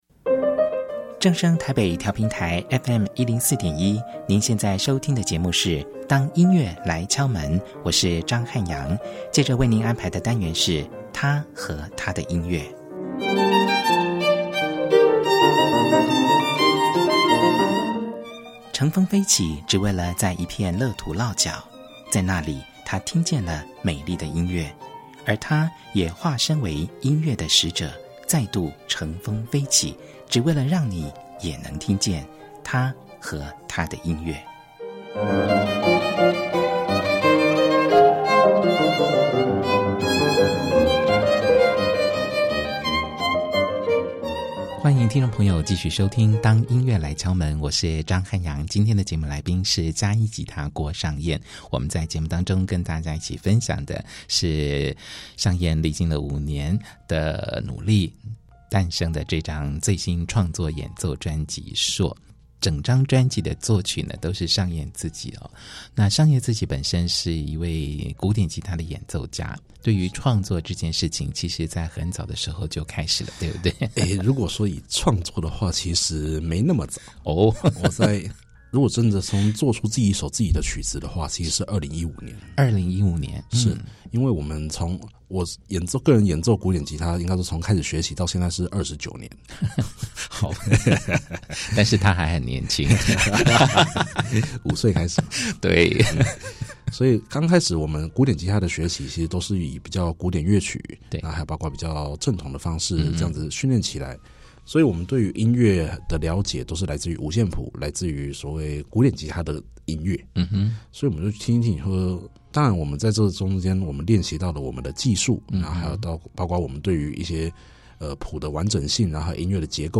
第二單元~訪問古典吉他演奏家